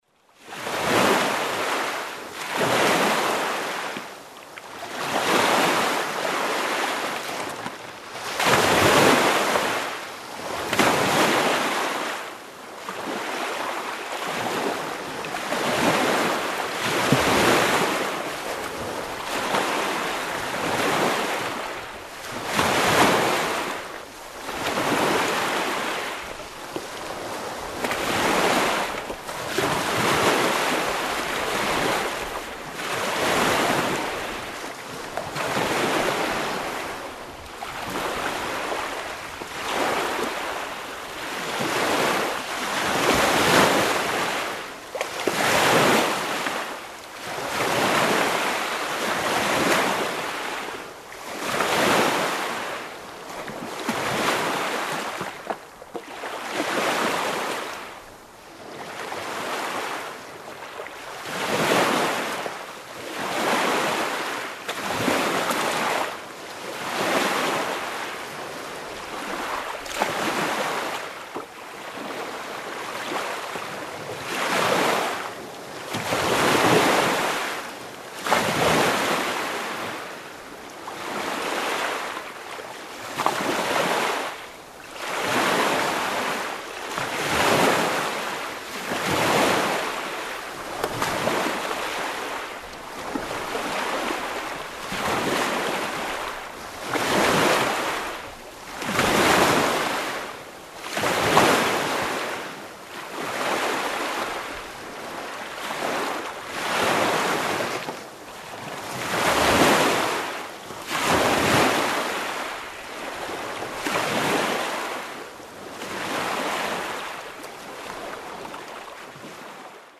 Звуки озера
Погрузитесь в атмосферу спокойствия с натуральными звуками озера: плеск воды, легкий ветерок, голоса водоплавающих птиц.
Шепот ряби на воде